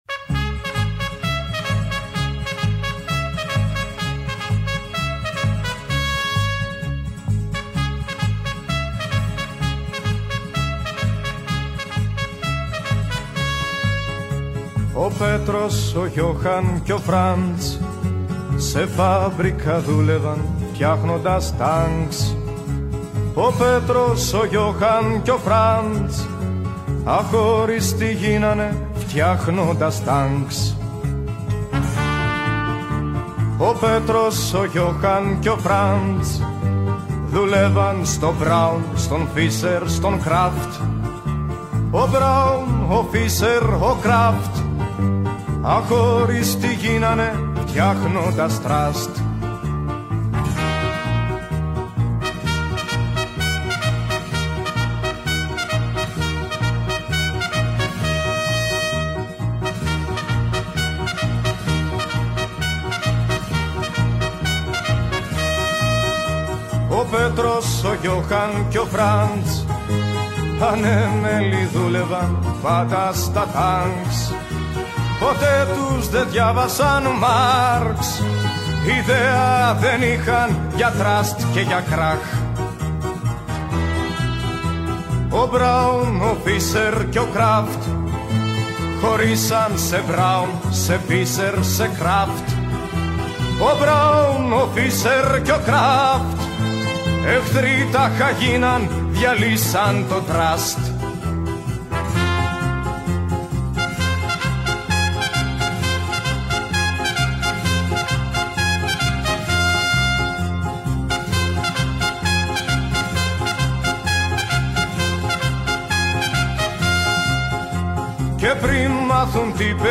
συζήτηση